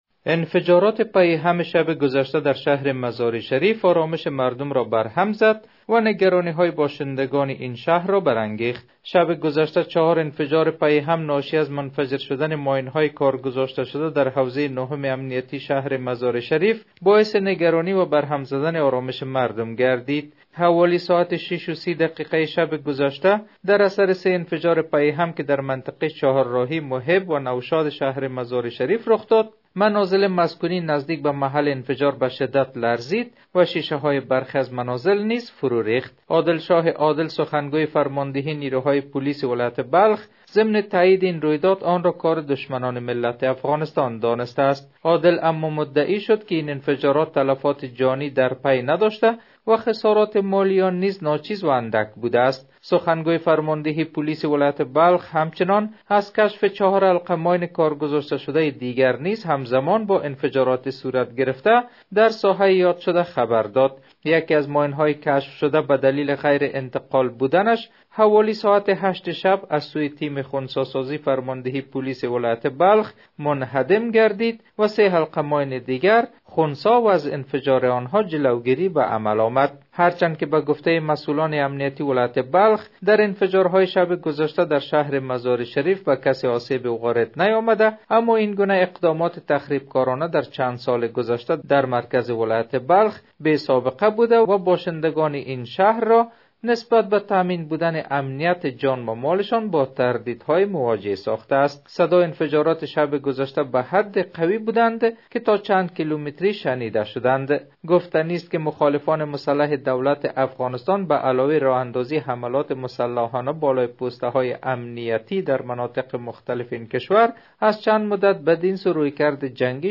خبرنگار رادیودری